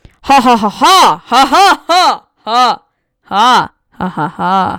laugh_orChNXI.mp3